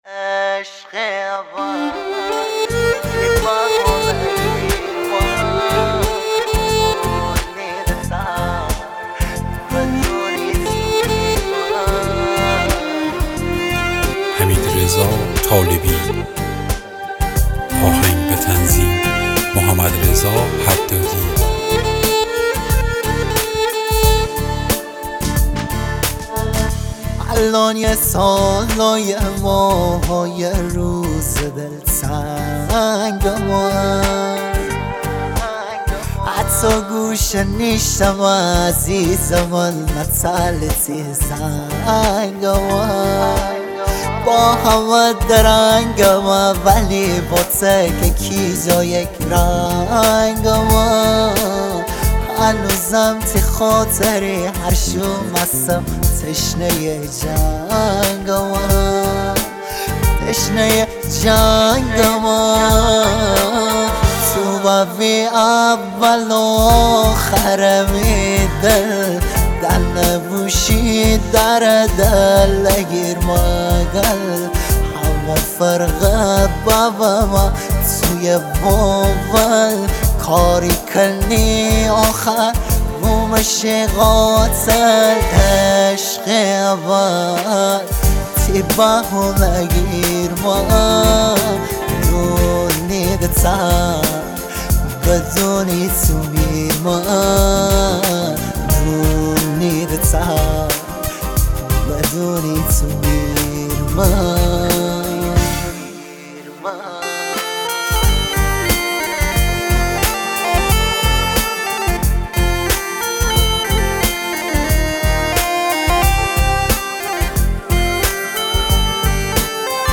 ریتمیک ( تکدست )
غمگین